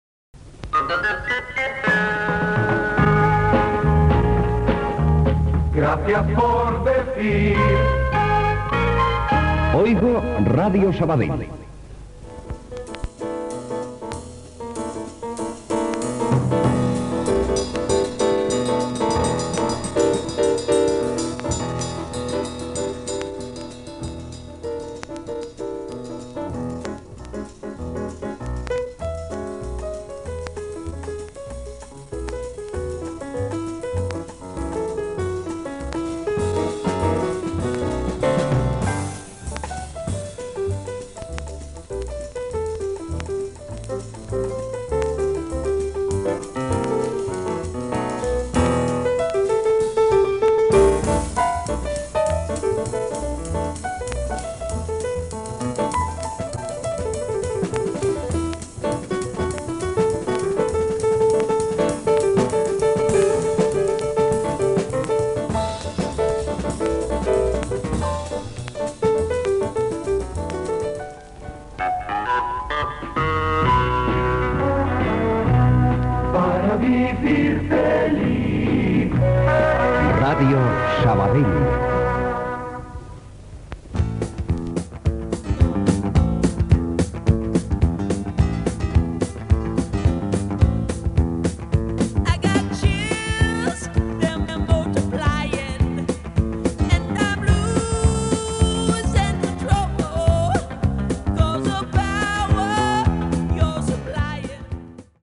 Indicatiu de l'emissora
música
Musical